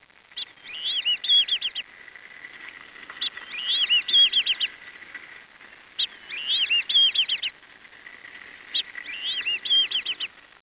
For example, chickens hear much less of the frequency range than humans do. So, a user who is a chicken in this world will hear only 125 to 2000 Hz instead of the 20Hz to 20kHz that humans can hear. To accomplish this, the audio sent to the user's headphones will be filtered appropriately.
bbwd2-chicken.wav